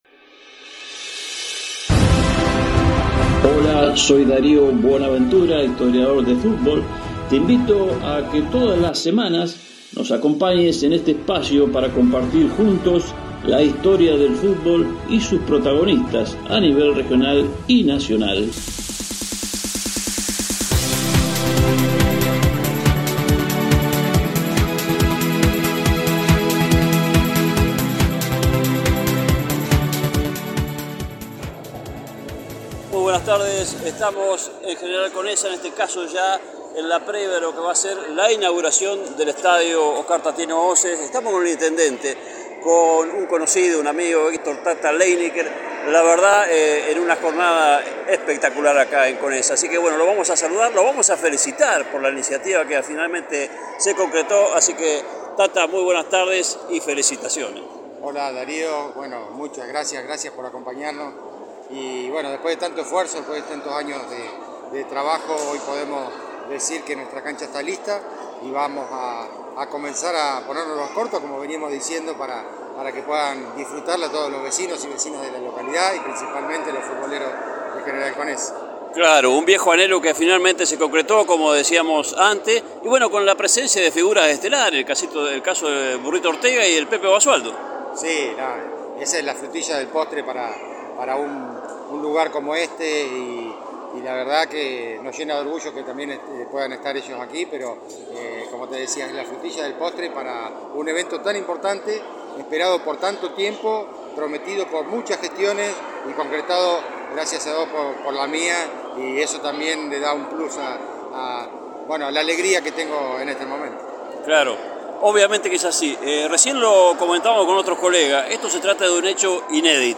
La palabra del Intendente municipal, Héctor Leineker: